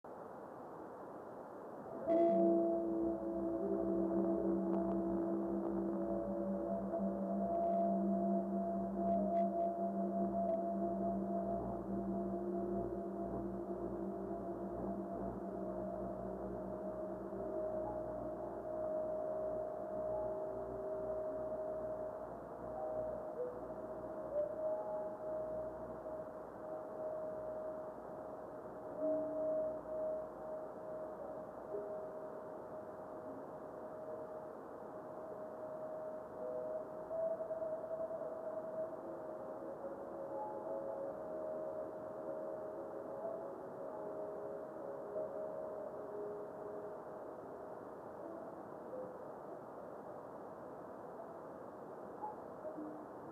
Observer's comment:  This was the best radio fireball of the night.
Moderate radio scatter.
Meteor reflection begins during the 1003 UT minute.